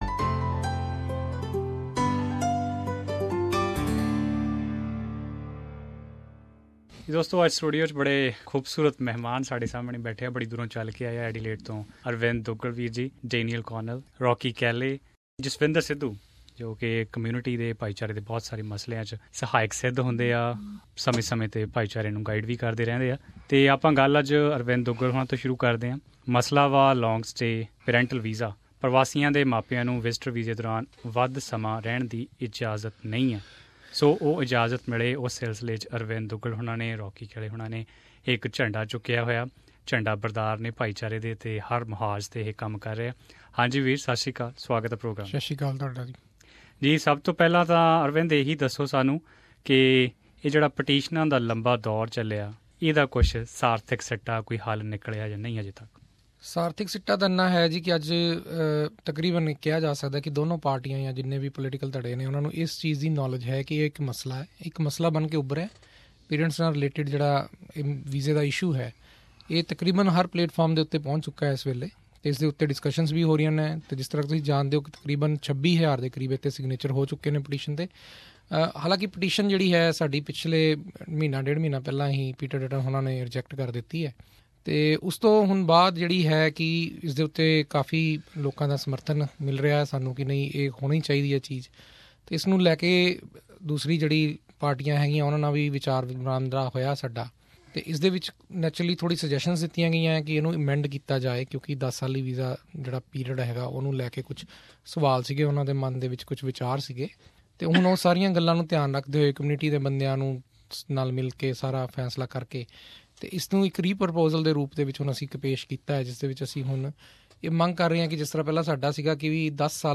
in conversation with a panel of guests who are raising their voice for this petition